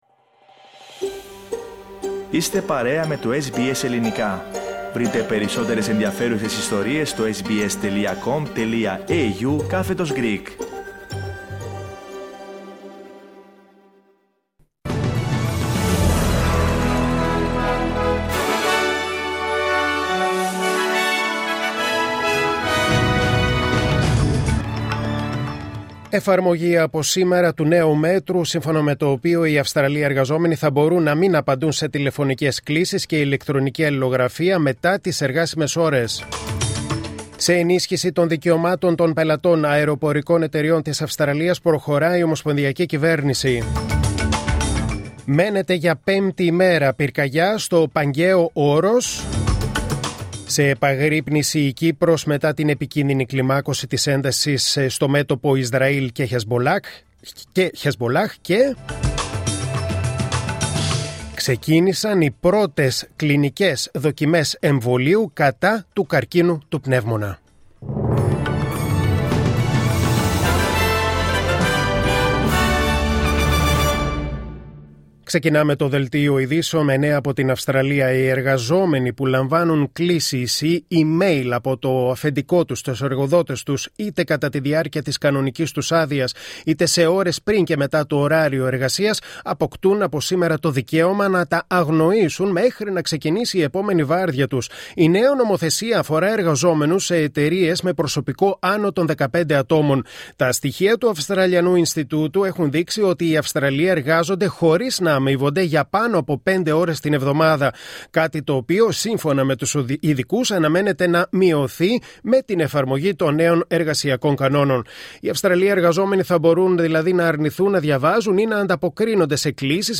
Δελτίο Ειδήσεων Δευτέρα 26 Αυγούστου 2024